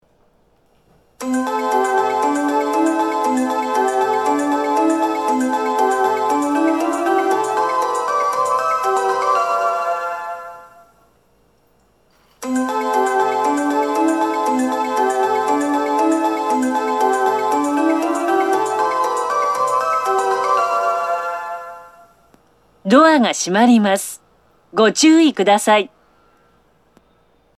発車メロディー
1・2番線共にメロディーの音量は小さめです。